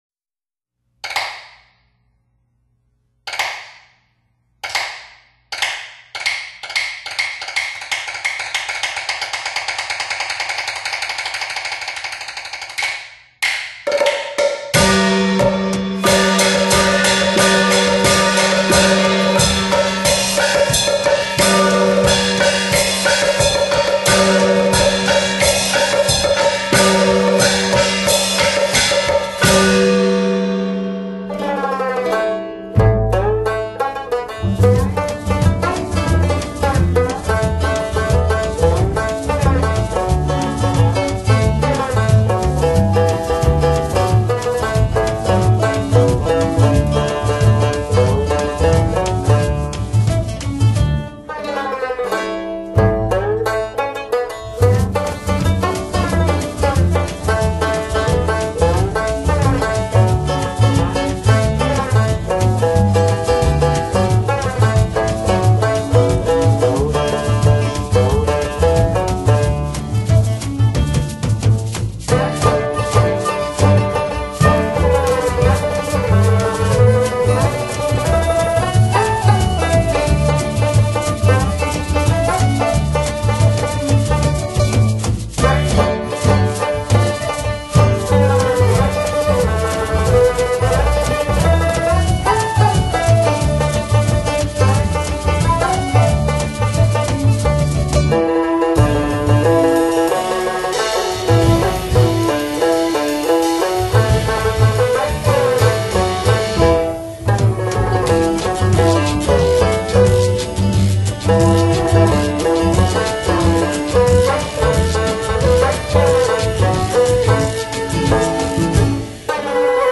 中阮 柳琴
爵士鼓 打擊 電貝司
洞簫 笛子
二胡 鑼鼓 打擊
既古老又新潮、既東方又藍調